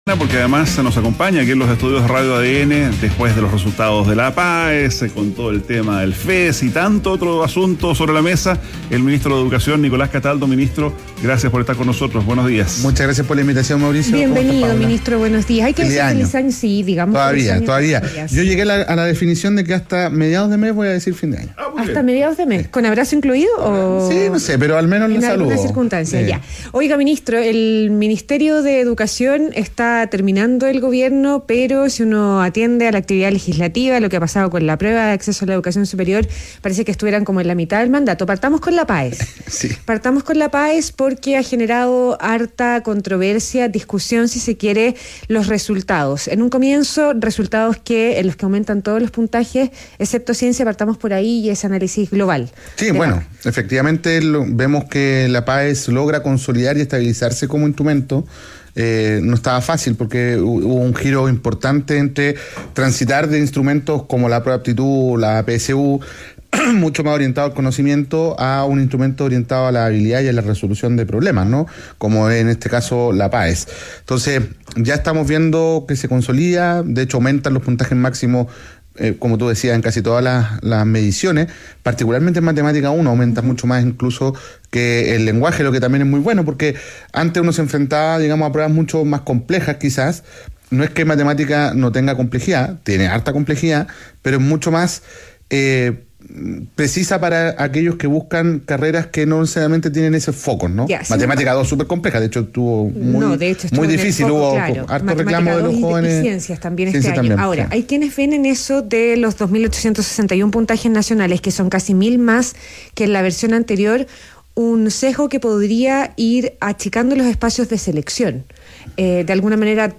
ADN Hoy - Entrevista a Nicolás Cataldo, ministro de Educación